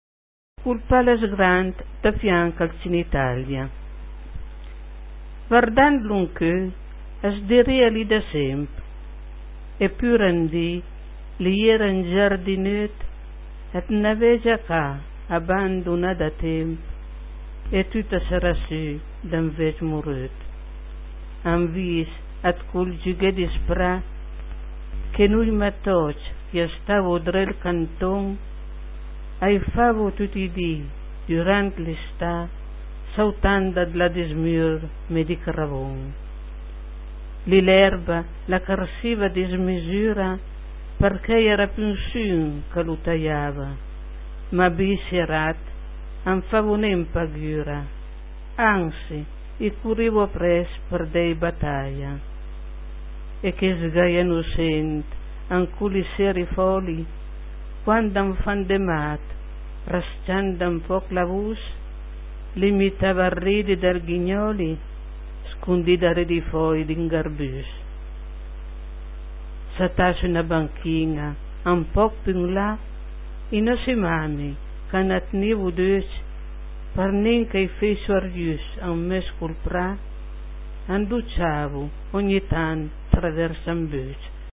Cliché chi sùta par sénti la puizìa recità da l'autùr ...